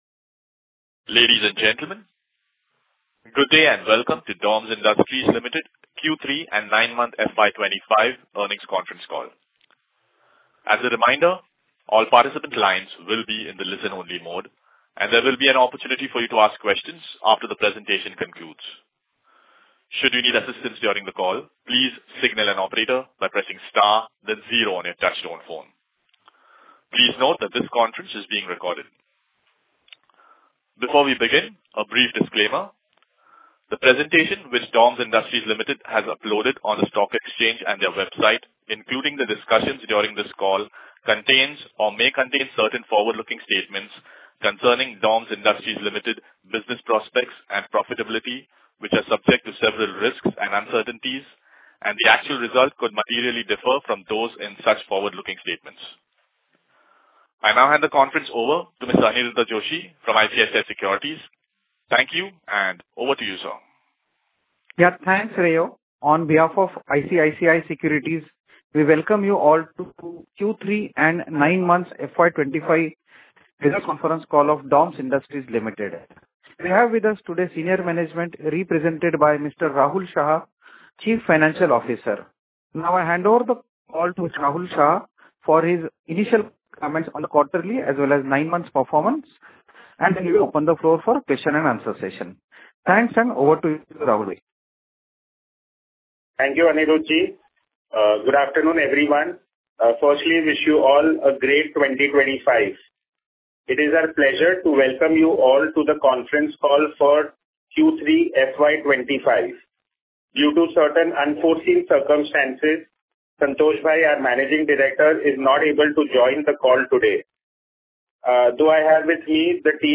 Concalls
Audio_Recording_Conf_Call_Q3FY2025.mp3